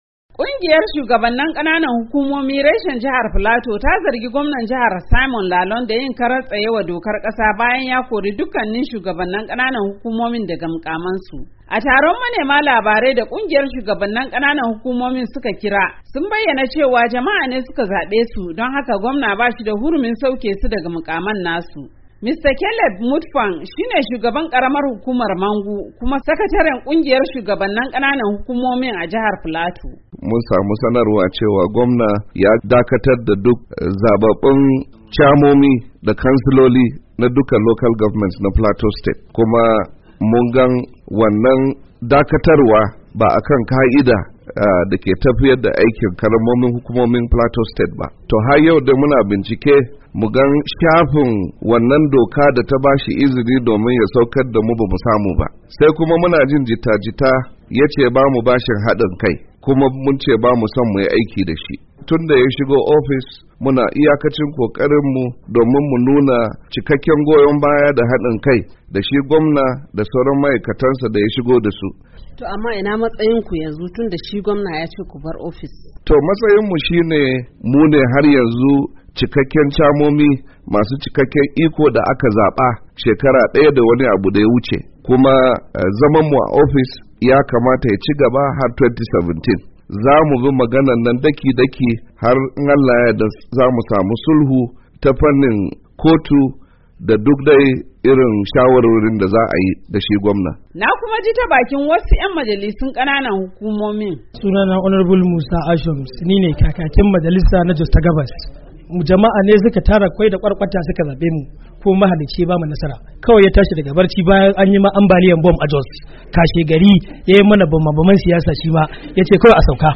Ga cikakken rahoton.